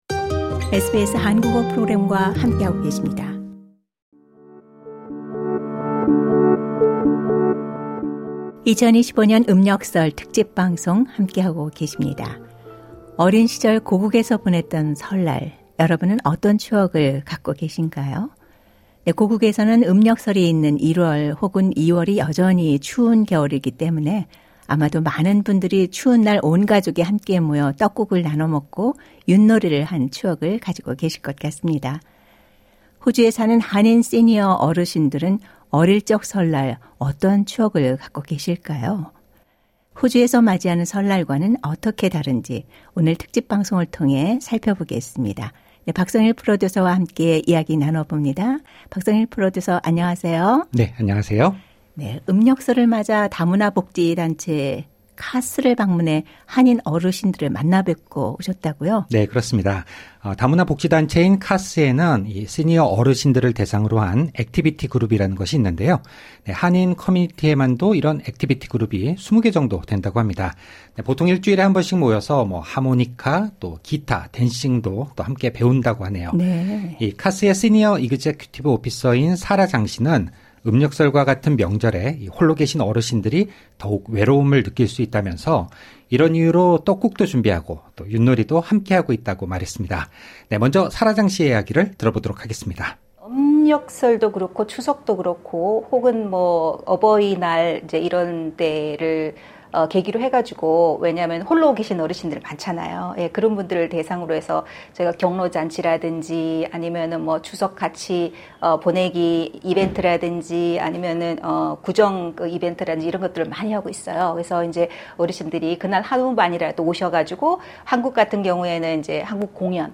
오늘 특집 방송을 통해 살펴보겠습니다.